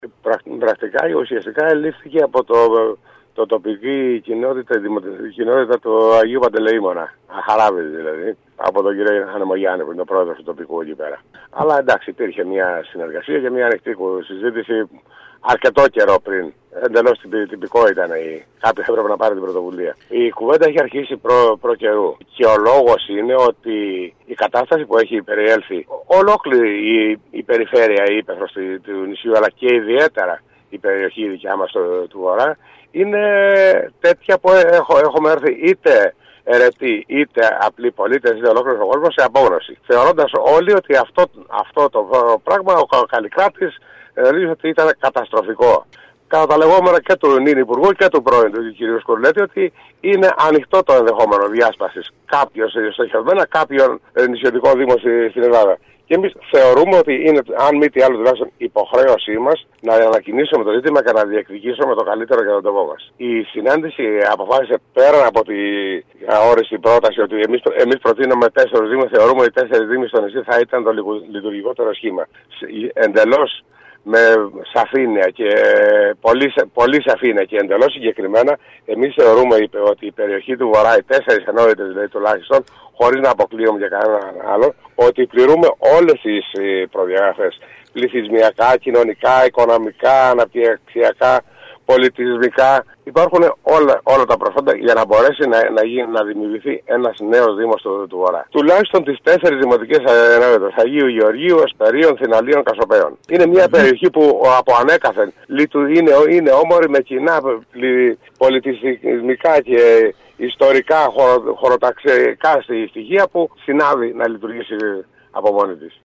Στην ΕΡΤ Κέρκυρας μίλησε ο αντιδήμαρχος Σπύρος Καλούδης